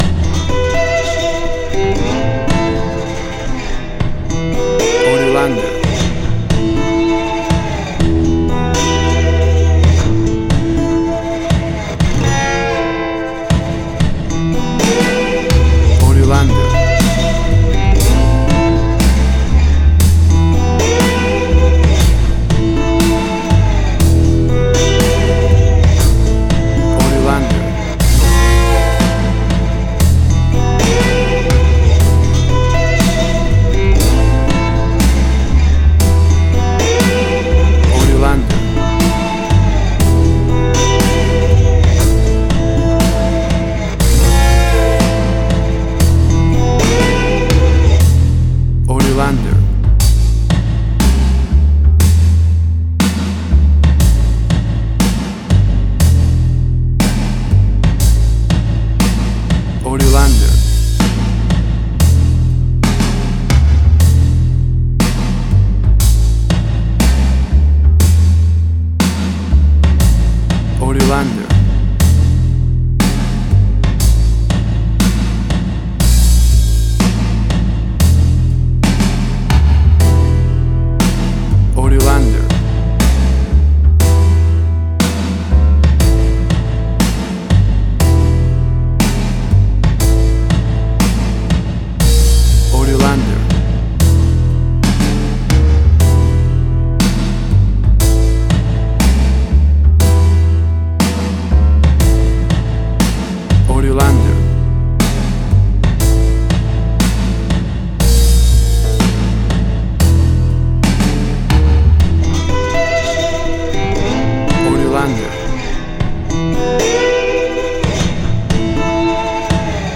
Old American Blues with a classic Vintage and raw vibe.
Tempo (BPM): 60